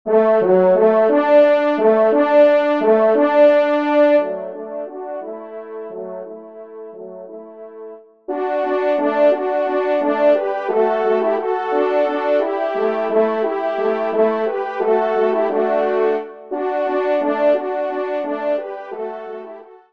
Genre :  Divertissement pour Trompes ou Cors
3e Trompe